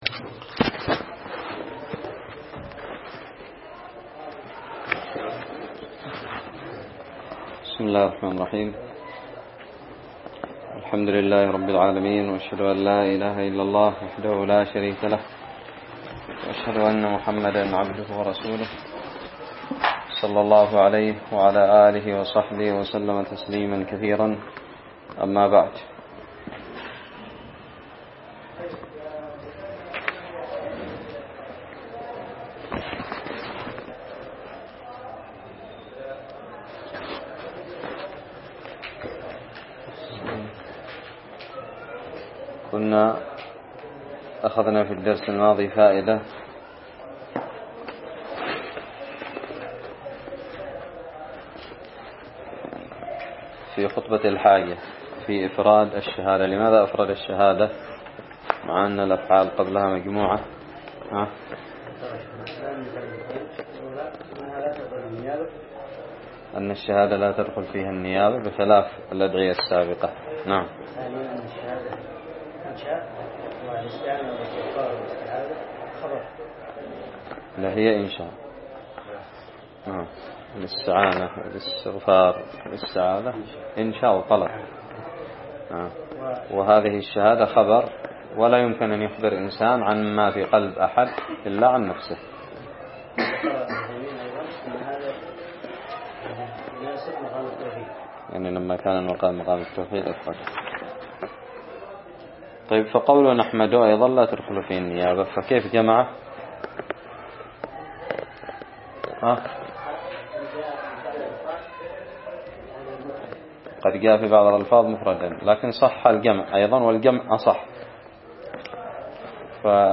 الدرس الثاني من شرح الرسالة التدمرية
ألقيت بدار الحديث السلفية للعلوم الشرعية بالضالع